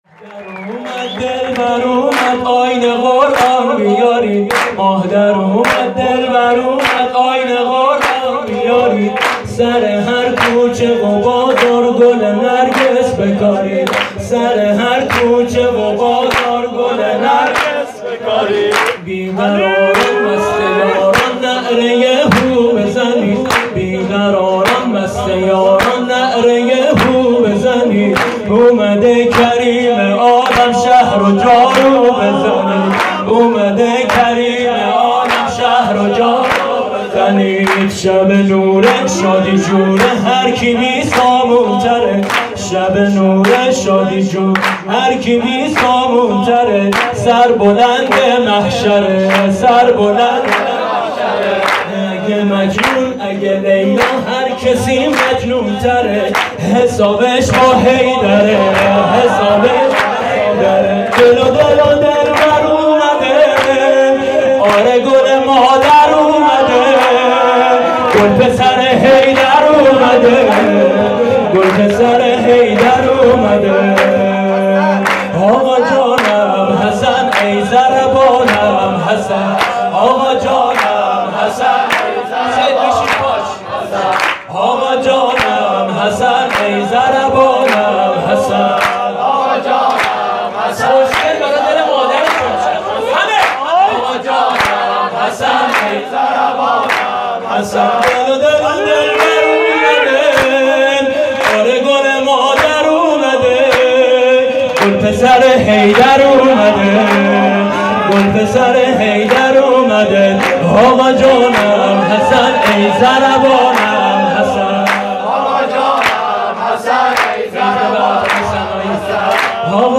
سرود آقا جانم حسن ای ضربانم حسن .mp3